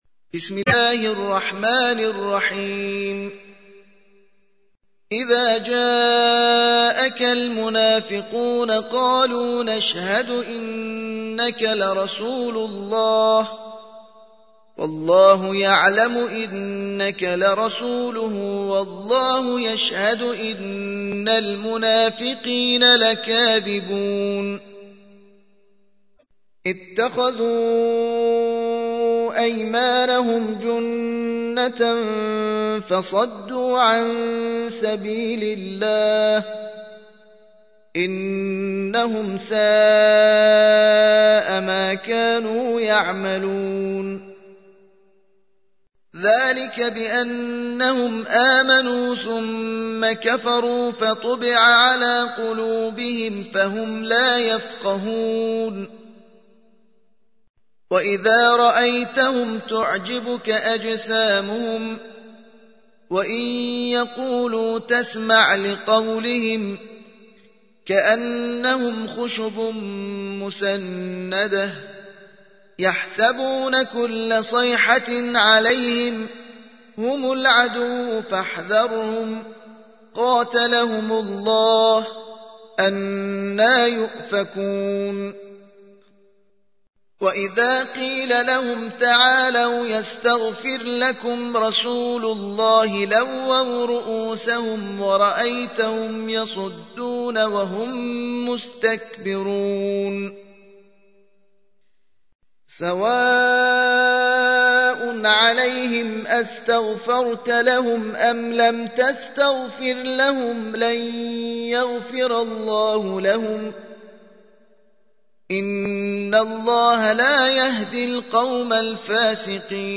سورة المنافقون / القارئ